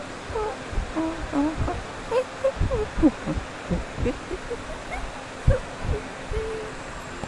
Tag: 纹理 车站 武吉-登